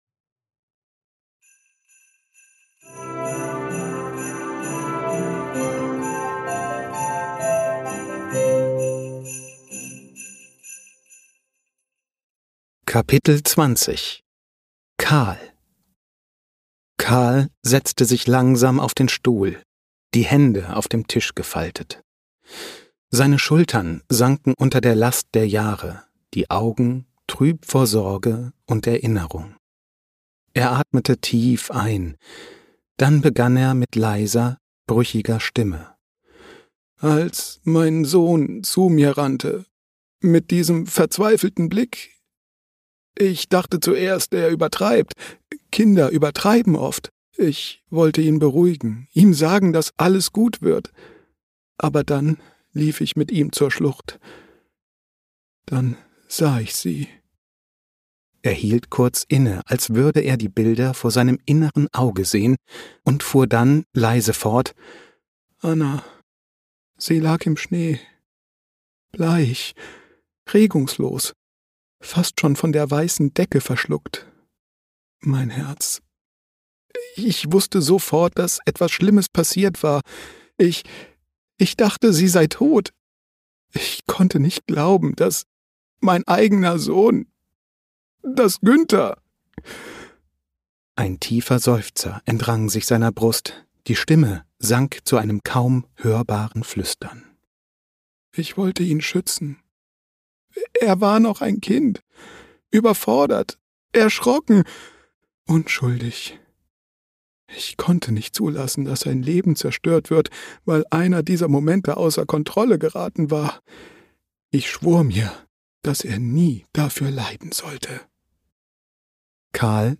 Lass dich von acht verzaubernden Stimmen in die
„Das Geheimnis der Glaskugel" ist eine Advents-Kriminalgeschichte,